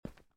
stonestep1.ogg